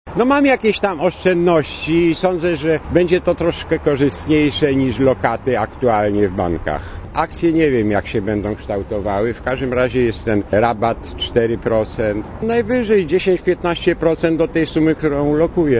* Mówi jeden z nabywców akcji*